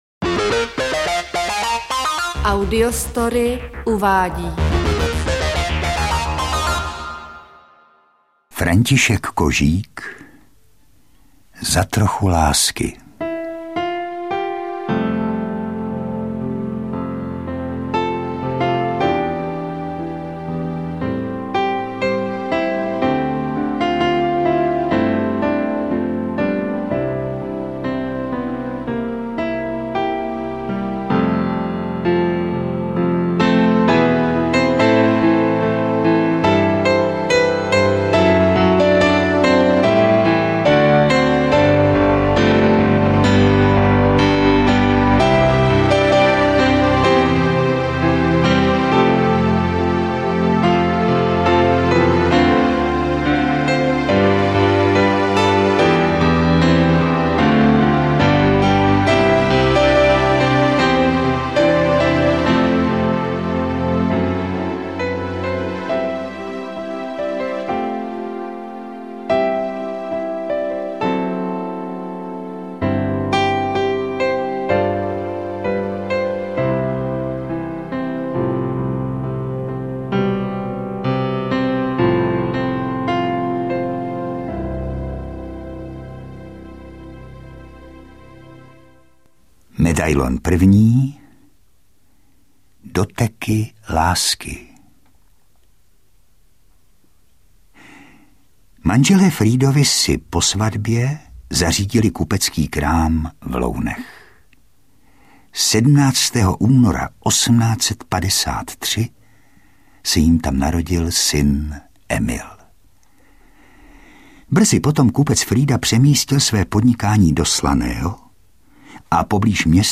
Interpreti:  Lukáš Hlavica, Růžena Merunková
Z profilů významných žen v životě Jaroslava Vrchlického je vytvořen zajímavý psychologický obraz této velké osobnosti české literatury. Dílo má rovněž dokumentační hodnotu a je v něm hojně užito citací z Vrchlického básní. Interpretují Miloš Hlavica a Růžena Merunková.
AudioKniha ke stažení, 13 x mp3, délka 4 hod. 20 min., velikost 238,1 MB, česky